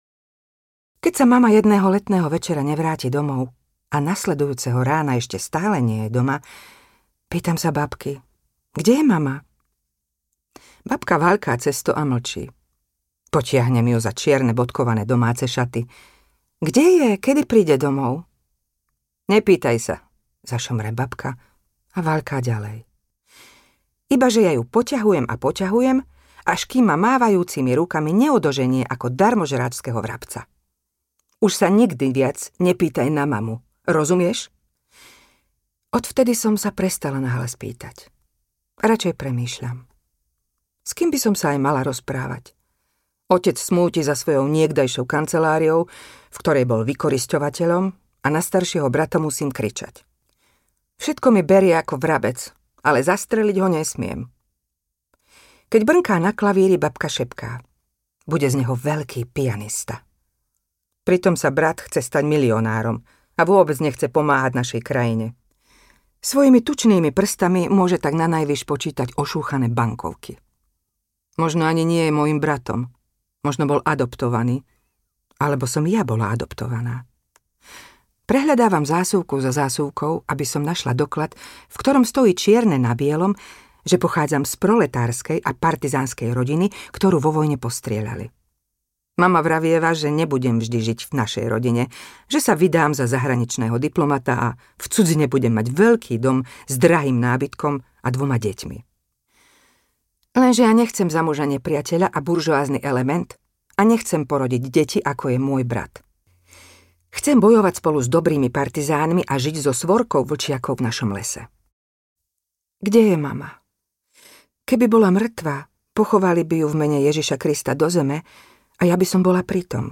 Na slepačích krídlach audiokniha
Ukázka z knihy
na-slepacich-kridlach-audiokniha